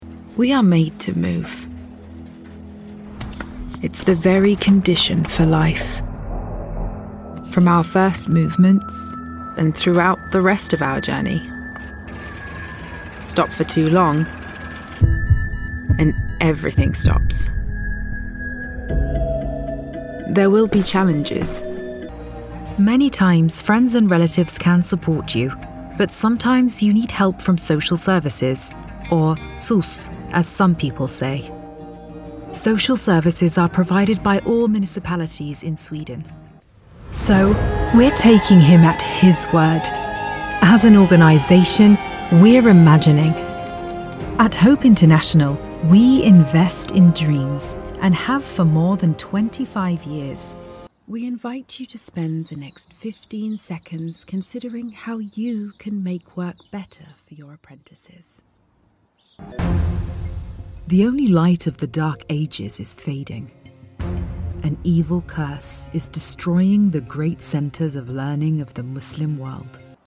Commercial Demo
Narration
I have a professional home recording studio and have lent my voice to a wide range of high-profile projects.
LA Booth, Rode, Audient id4
DeepLow
TrustworthyAuthoritativeConfidentFriendlyExperiencedReliable